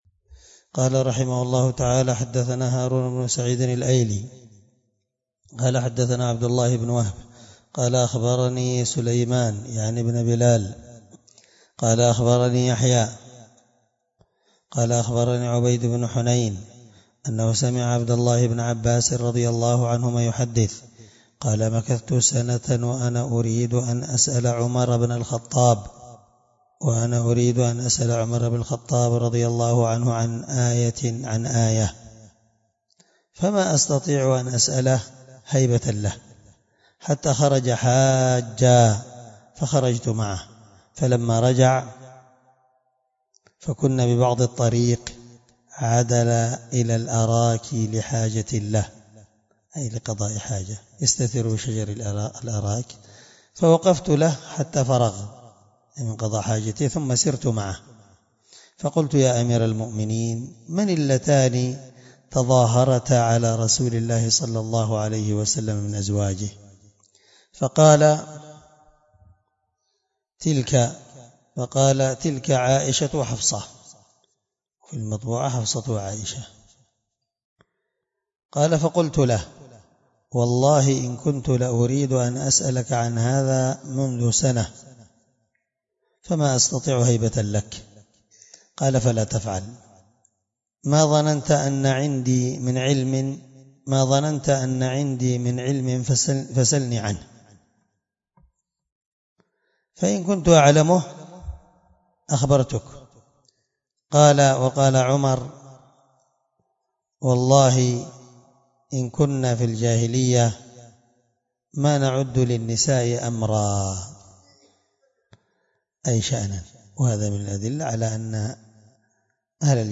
الدرس7من شرح كتاب الطلاق حديث رقم(1479) من صحيح مسلم